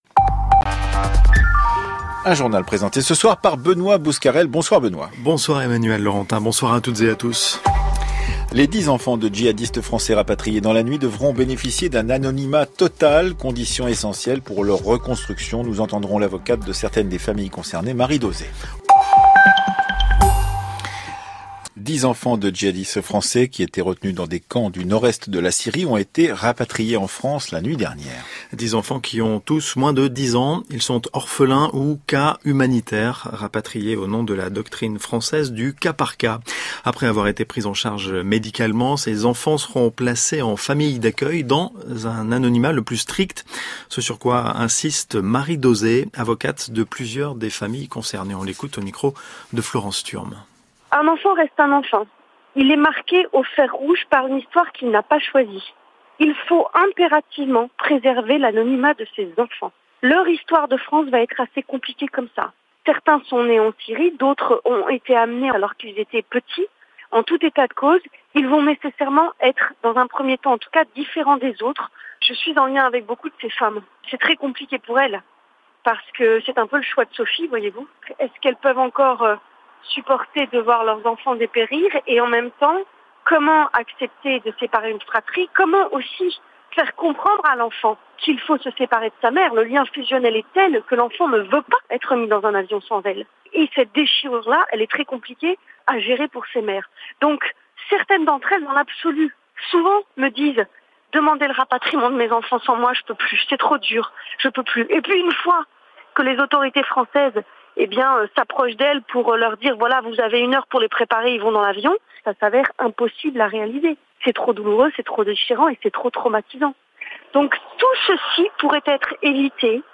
JOURNAL DE 18H